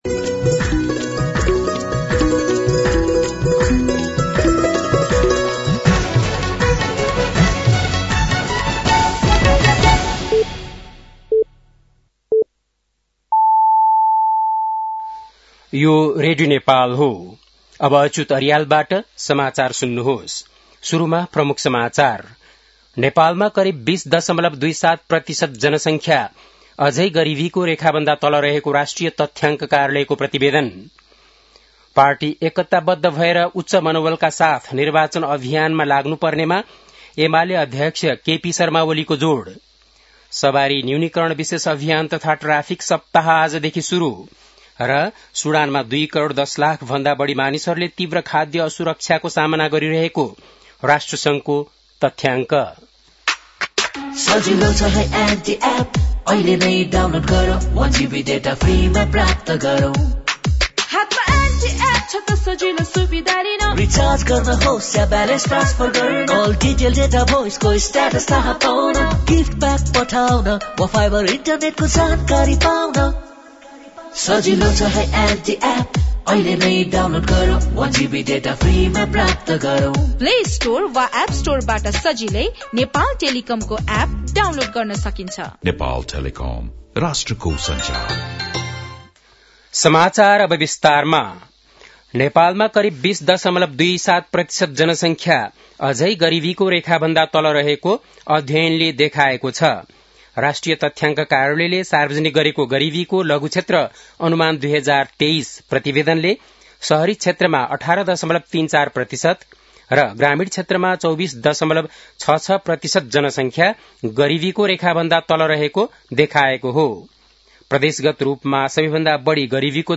बेलुकी ७ बजेको नेपाली समाचार : २६ पुष , २०८२
7.-pm-nepali-news-.mp3